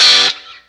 Track 02 - Guitar Stab OS 08.wav